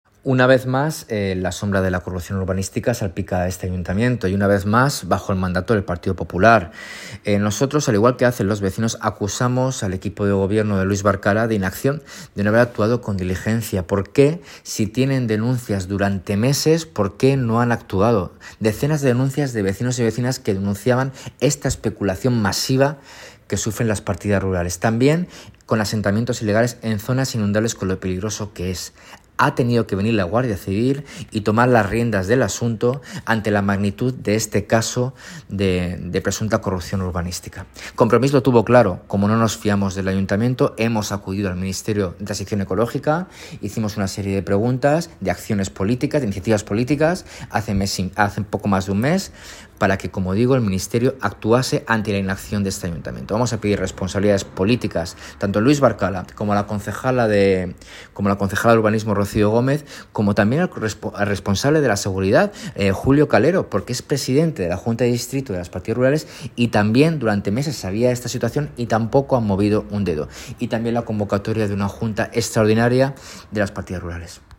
Declaraciones del concejal Rafael Más de Compromis:
audio-rafa-mas.mp3